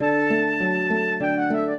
flute-harp
minuet0-4.wav